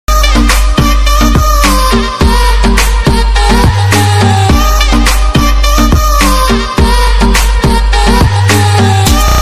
10-saniyelik-sark-kesiti-6_Ro5yurI.mp3